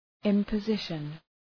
Προφορά
{,ımpə’zıʃən}